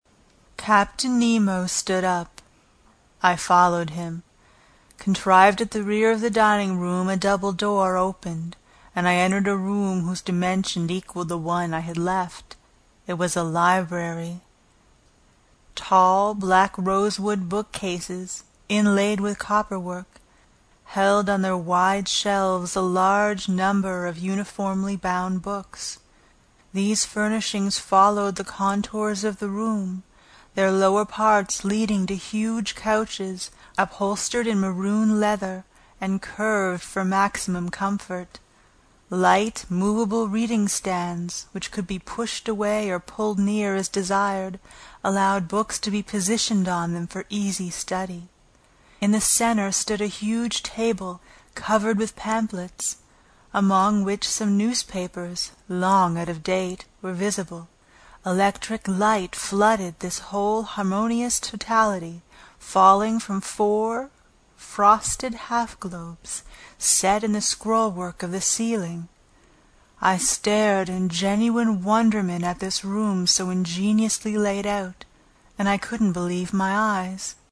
英语听书《海底两万里》第150期 第11章 诺第留斯号(1) 听力文件下载—在线英语听力室
在线英语听力室英语听书《海底两万里》第150期 第11章 诺第留斯号(1)的听力文件下载,《海底两万里》中英双语有声读物附MP3下载